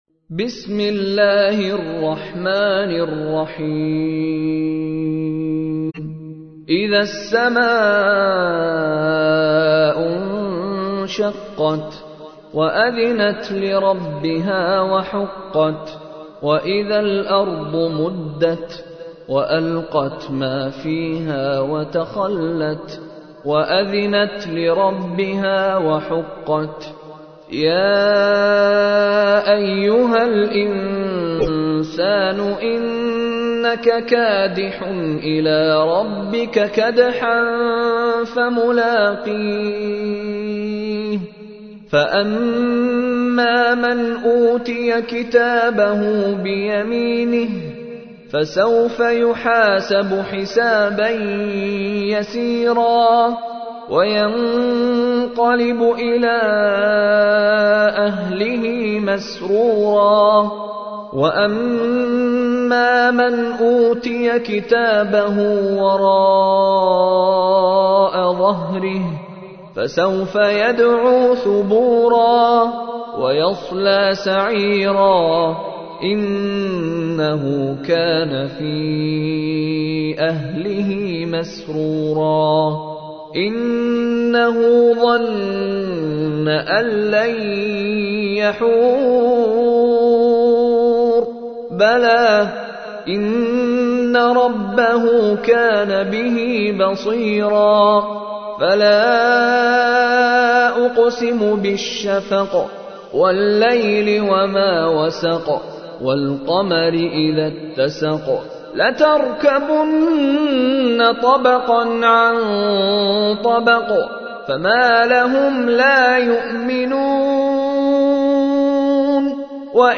تحميل : 84. سورة الانشقاق / القارئ مشاري راشد العفاسي / القرآن الكريم / موقع يا حسين